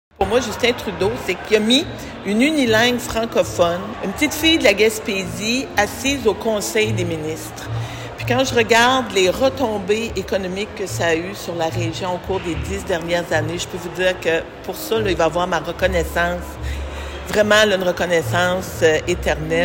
La ministre des Pêches et des Océans s’est entretenue avec les médias à ce sujet jeudi, lors d’une rencontre à Gaspé.